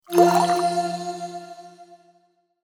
Download Potion Use sound effect for free.
Potion Use